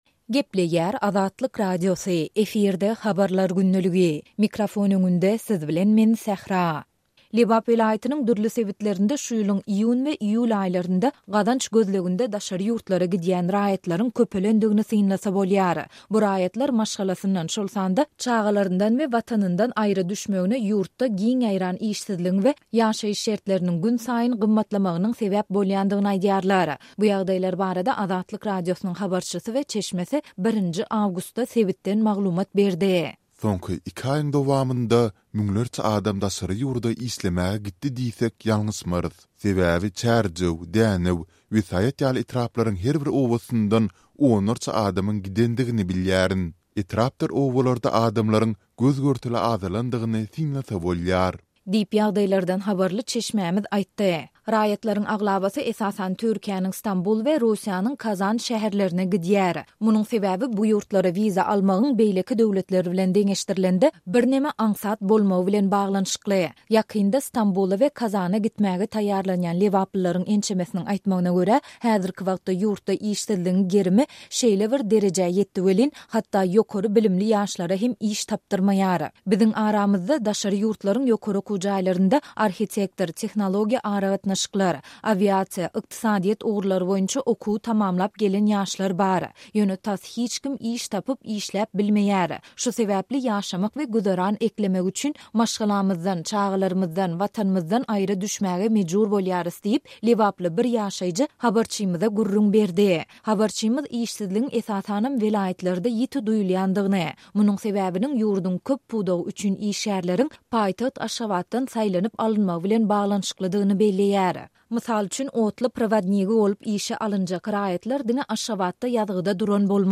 Bu ýagdaýlar barada Azatlyk Radiosynyň habarçysy we çeşmesi 1-nji awgustda sebitden maglumat berdiler.